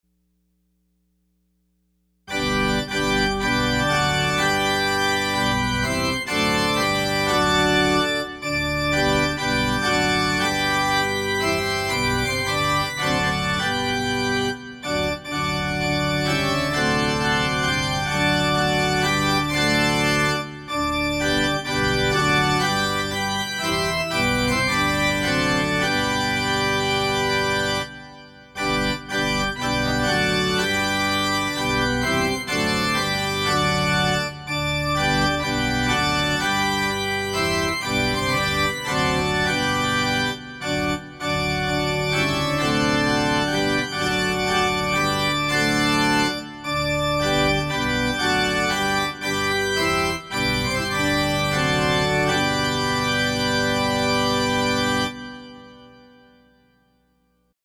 Offering Hymn – How wondrous and great